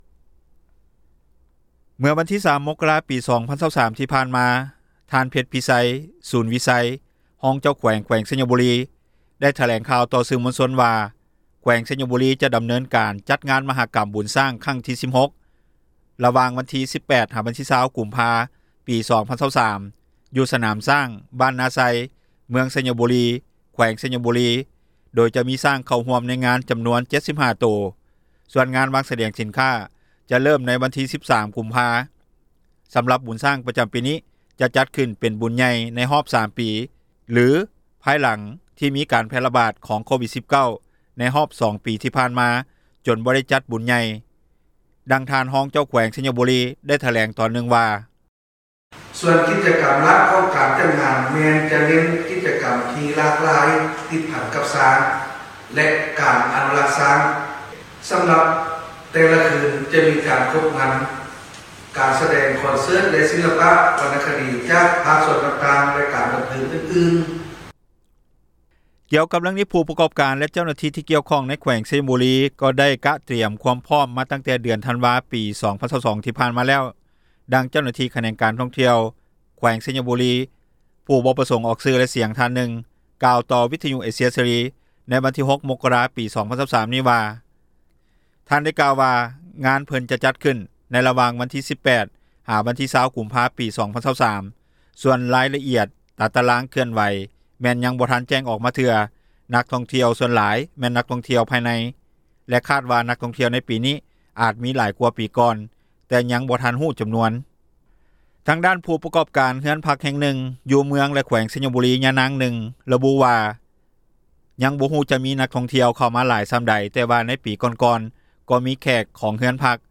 ດັ່ງທ່ານຮອງເຈົ້າແຂວງ ແຂວງໄຊຍະບູຣີ ໄດ້ຖແລງຕອນນຶ່ງວ່າ:
ດັ່ງຍານາງ ກ່າວຕໍ່ວິທຍຸ ເອເຊັຽ ເສຣີ ໃນມື້ດຽວກັນນີ້ວ່າ: